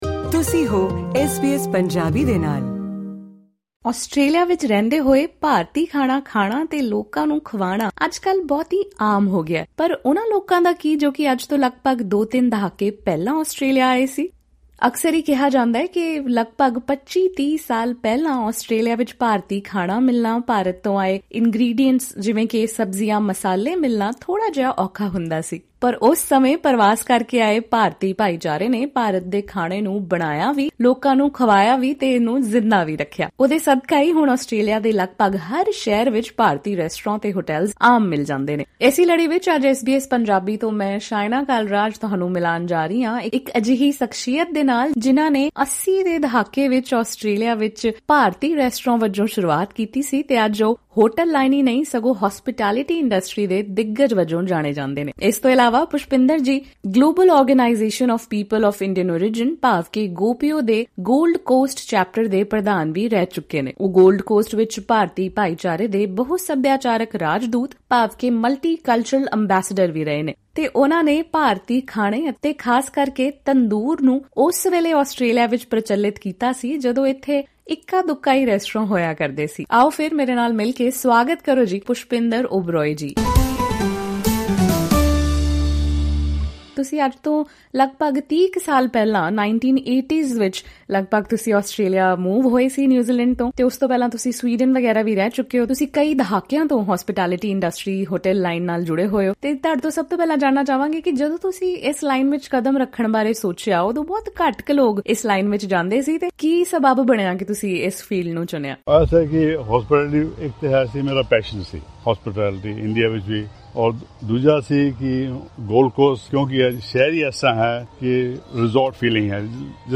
Speaking to SBS Punjabi, he also shared several tips for succeeding in the Australian hotel industry.
ਪੂਰੀ ਗੱਲਬਾਤ ਸੁਣੋ ਇਸ ਪੌਡਕਾਸਟ ਵਿੱਚ।